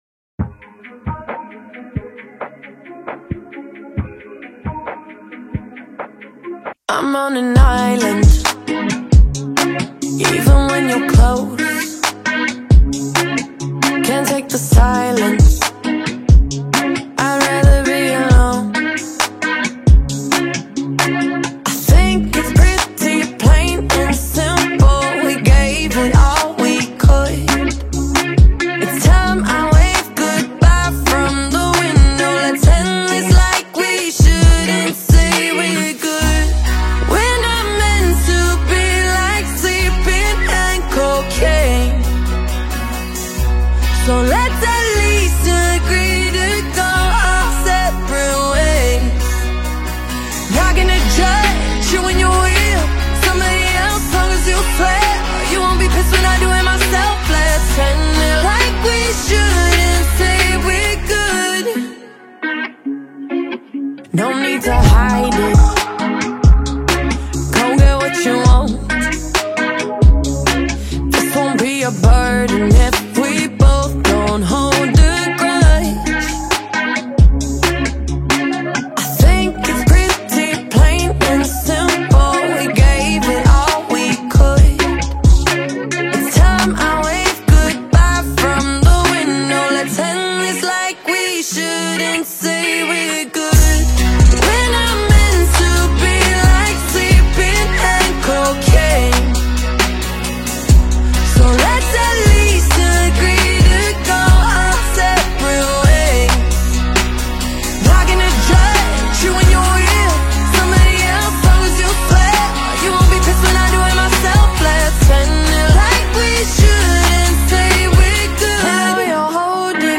Su estilo es pop.